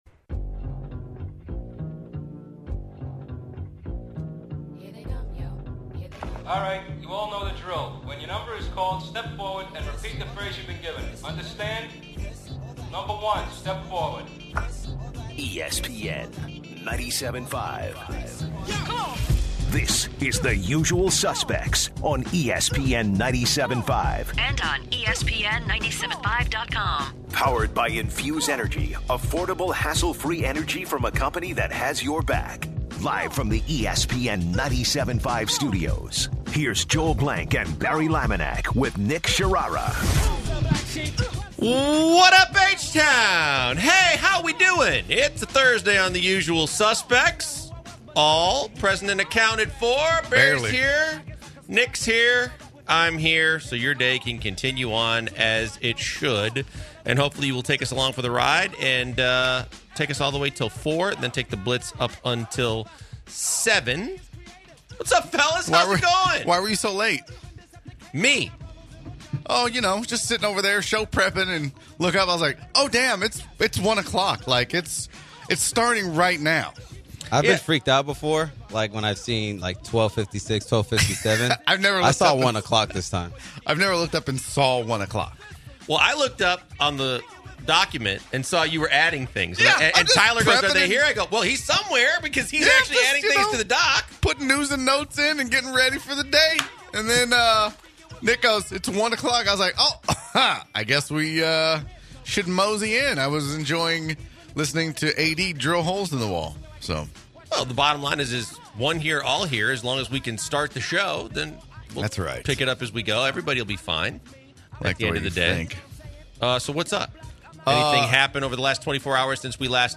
The guys are all in the studio today as they open up the show with stories of being old and dietary restrictions.